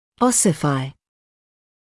[‘ɔsɪfaɪ][‘осифай]окостеневать, оссифицироваться